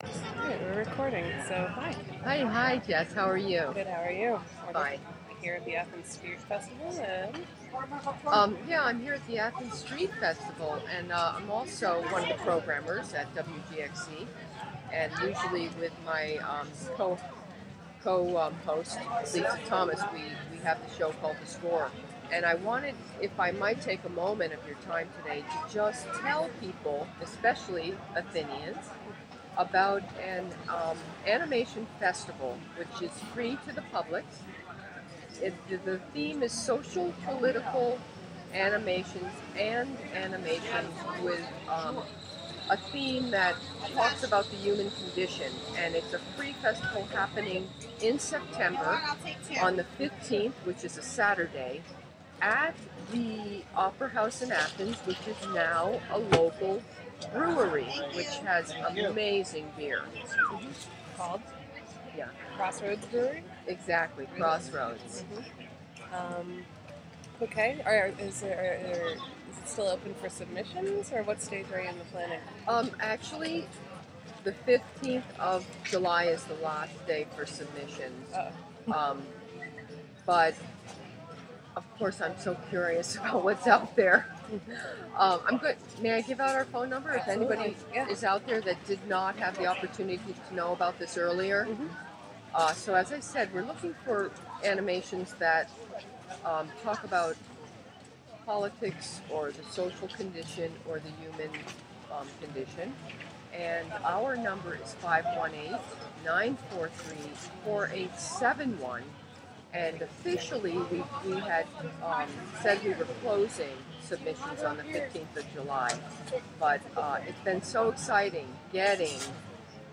WGXC will broadcast live at the Athens Street Fest...
WGXC 90.7-FM: Radio for Open Ears Interview